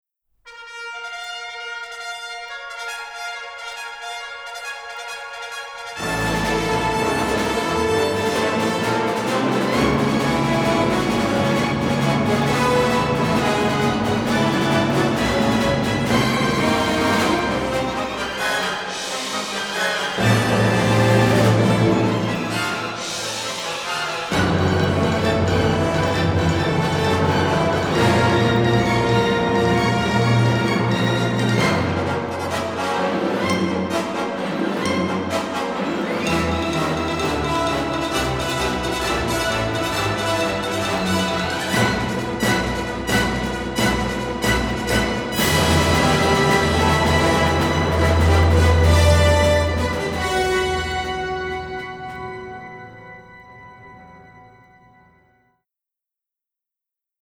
records and mixes at AIR Studios in London